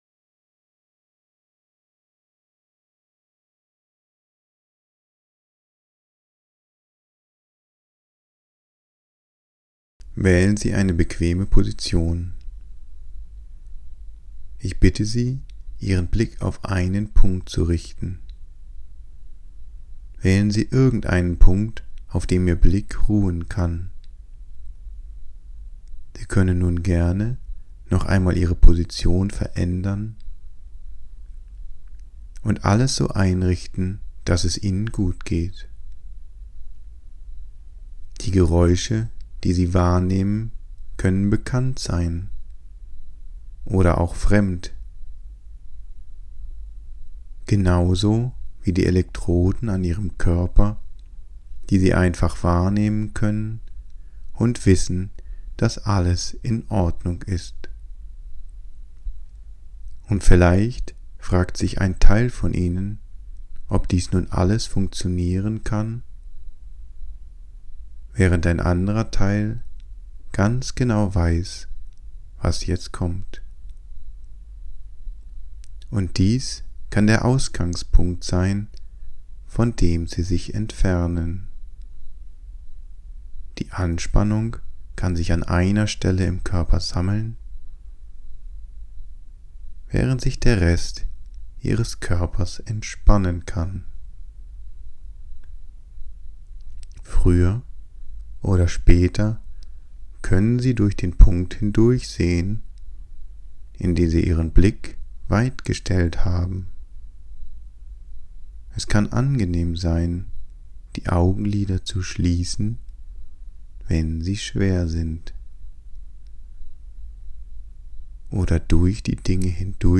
hypnotic_suggestion_deep_sleep.mp3